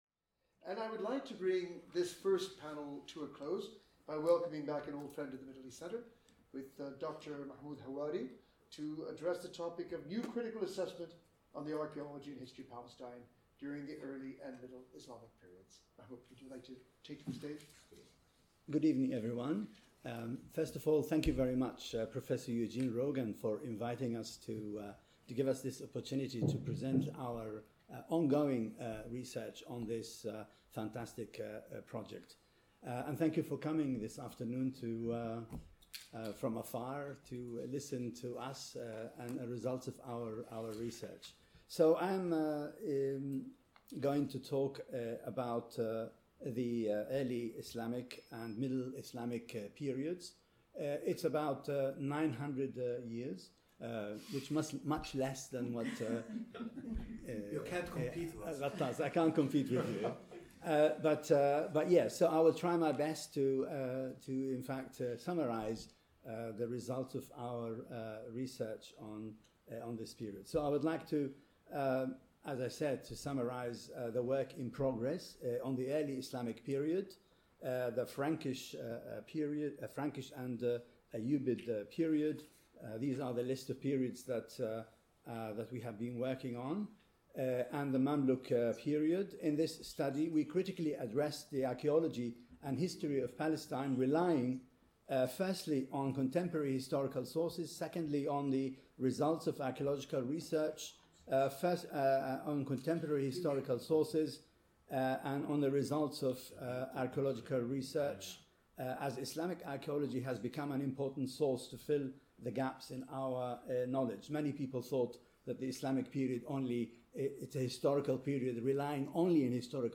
This paper was part of a panel chaired by Professor Eugene Rogan.